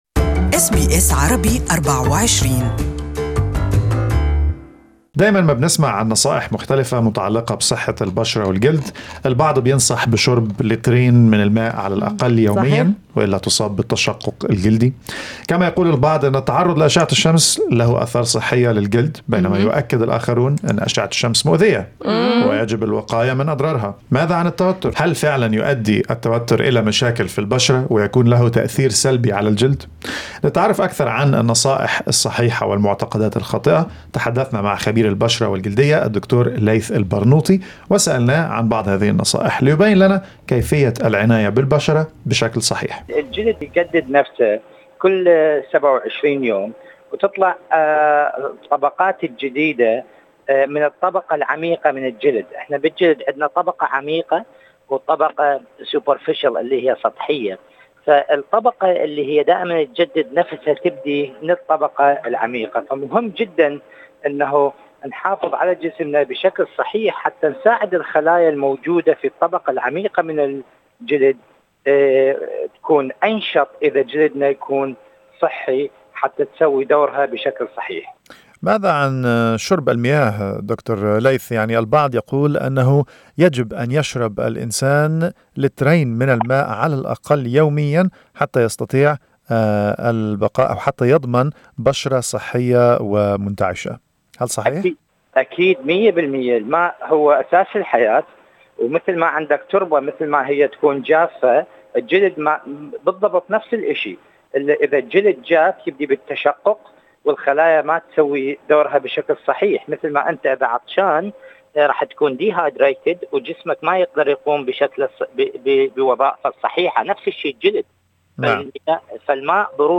With so many skincare tips online, it can be hard to tell which are misconceptions and which are real. This interview